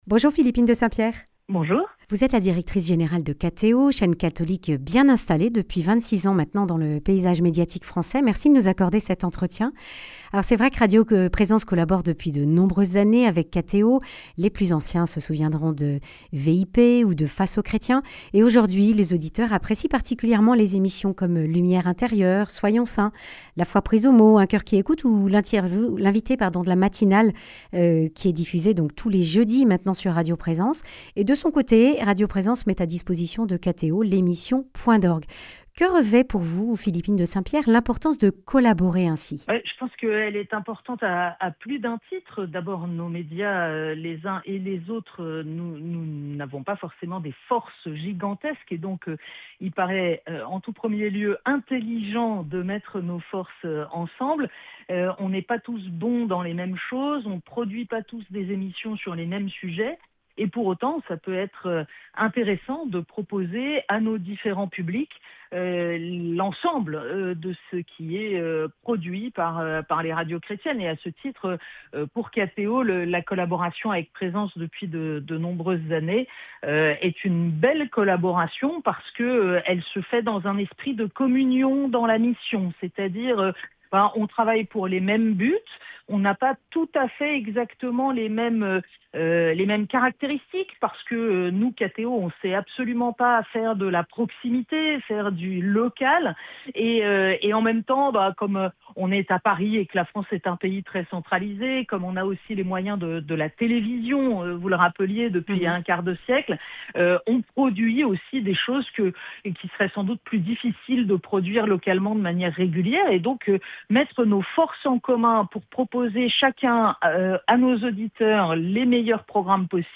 vendredi 28 novembre 2025 Le grand entretien Durée 10 min